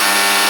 - Added buzzer sound when final round ends with a time-out. 2025-09-24 15:20:09 -04:00 86 KiB Raw History